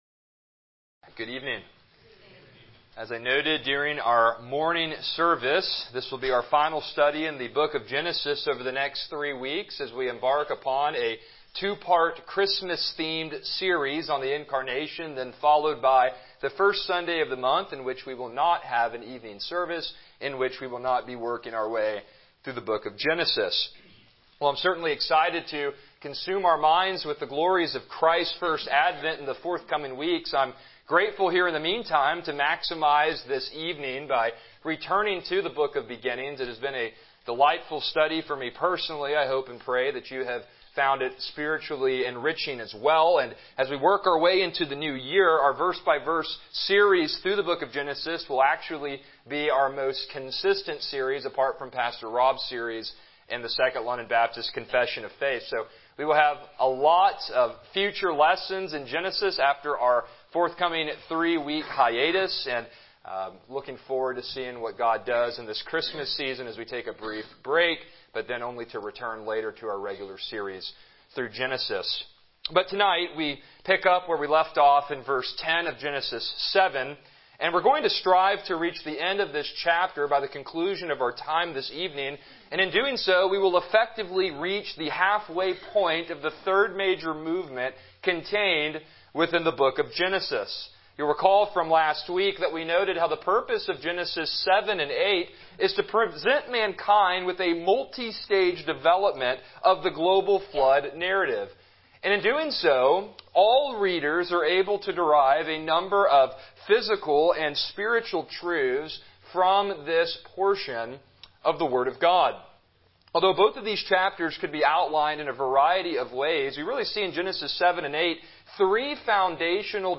Passage: Genesis 7:10-24 Service Type: Evening Worship